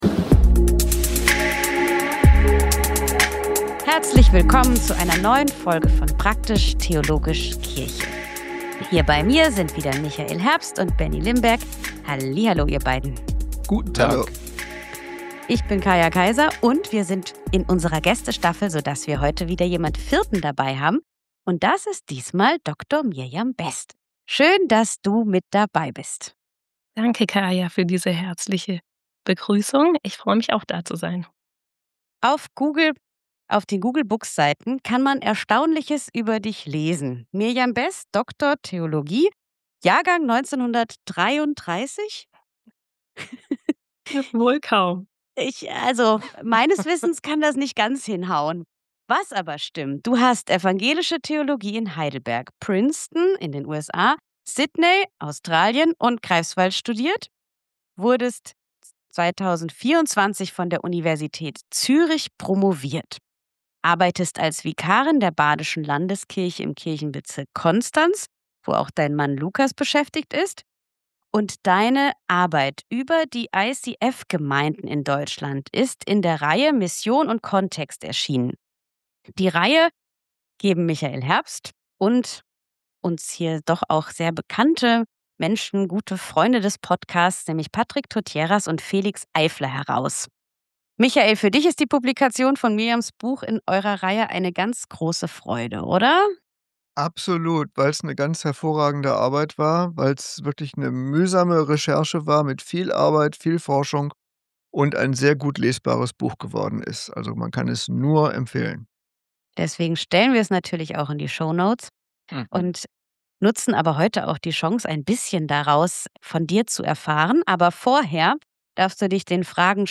Wir befinden uns in unserer zweiten Staffel, in der wir mit faszinierenden Gästen ins Gespräch kommen! Wir reden mit ihnen über das, was sie begeistert und wo sie Chancen und neue Perspektiven für die Kirche sehen.